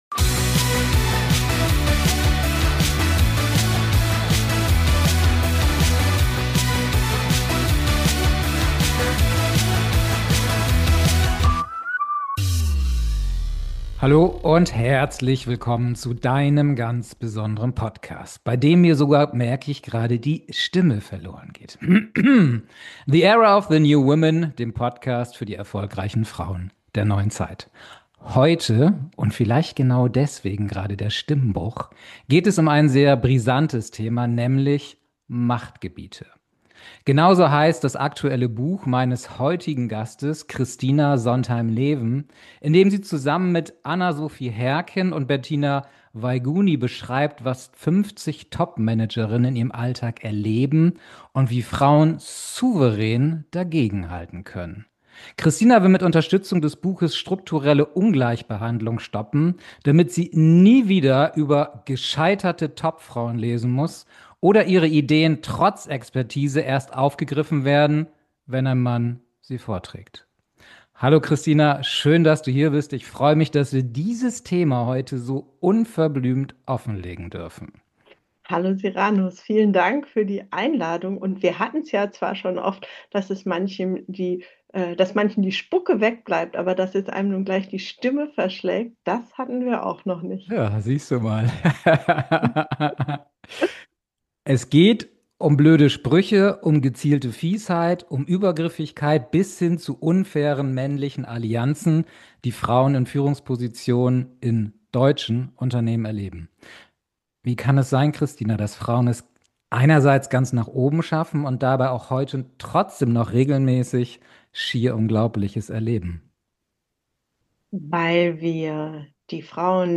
Ein Gespräch über Mut, Macht und die innere Haltung erfolgreicher Frauen.